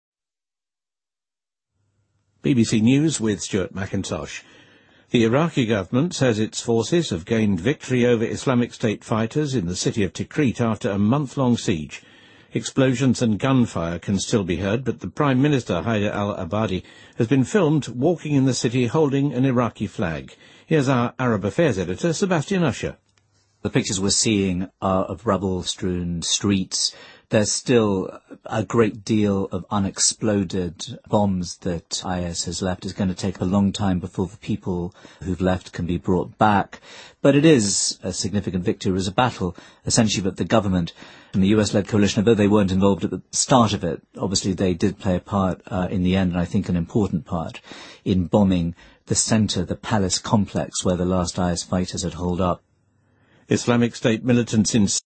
BBC news,2015-04-02新闻